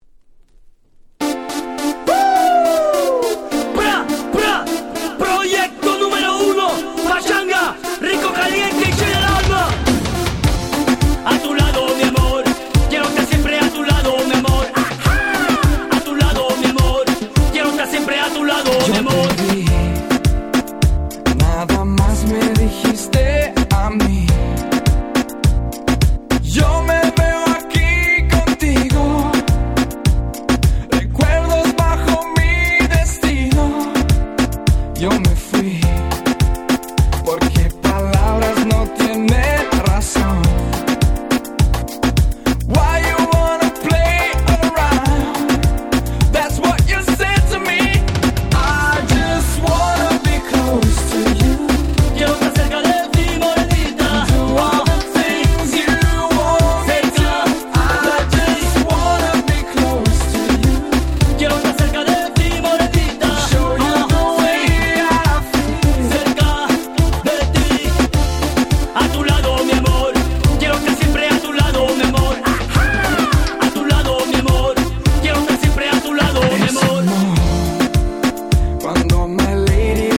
06' Very Nice Cover Reggaeton !!
パチャンガ レゲエ レゲトン 00's キャッチー系